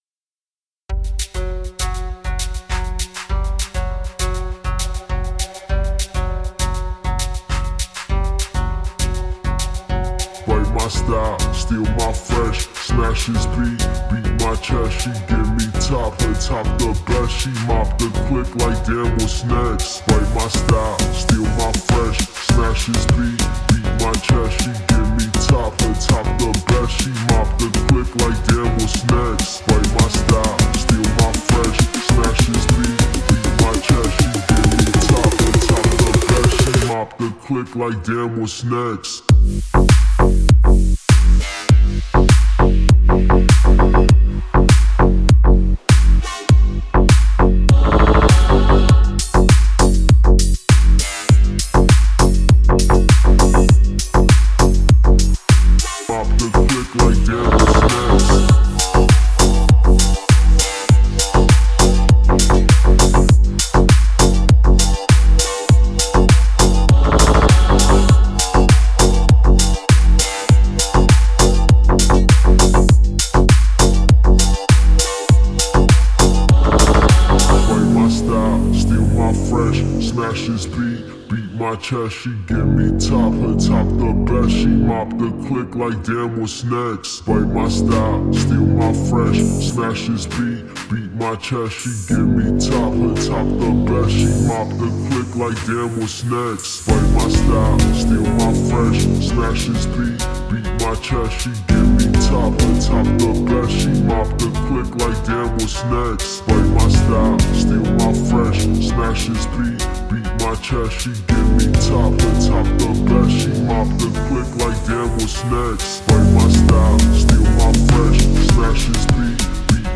это динамичный трек в жанре EDM